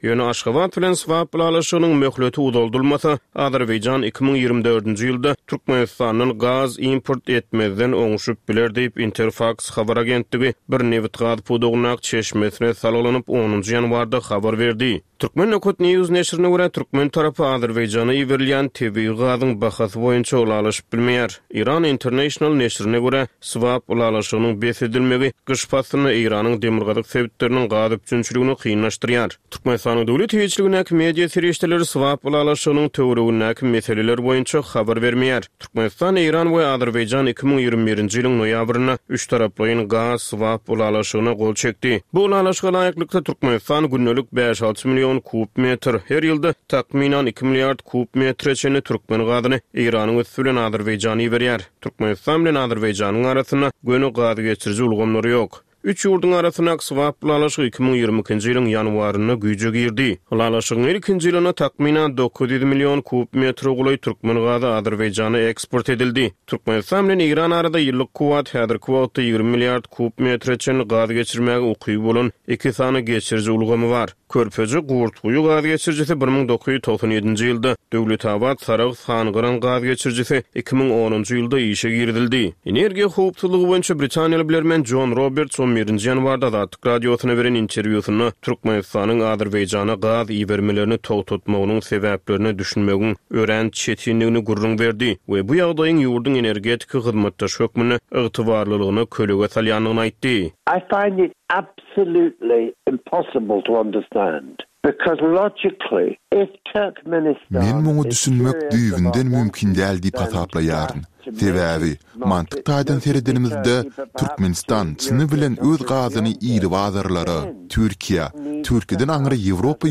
Tutuş geçen bir hepdäniň dowamynda Türkmenistanda we halkara arenasynda bolup geçen möhüm wakalara syn. Bu ýörite programmanyň dowamynda hepdäniň möhüm wakalary barada synlar, analizler, söhbetdeşlikler we kommentariýalar berilýär.